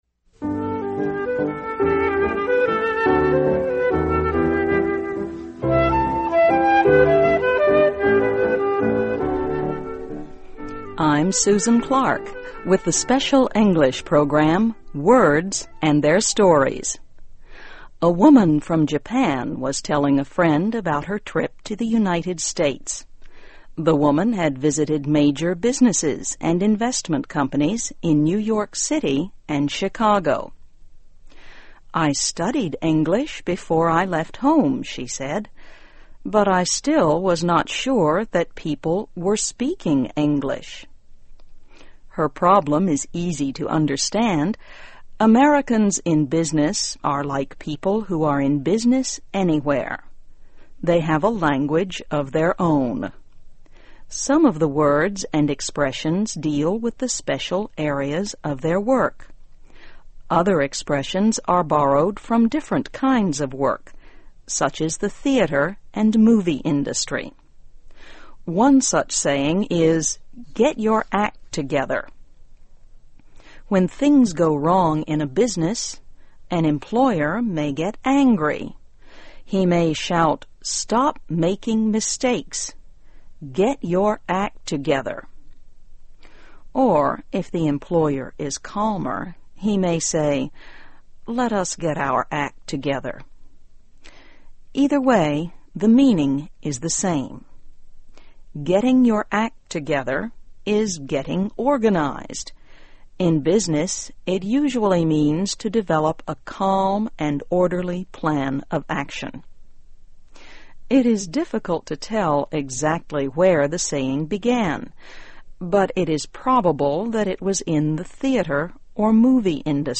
Words and Their Stories: Get Your Act Together & Cut to the Chase (VOA Special English 2009-06-11)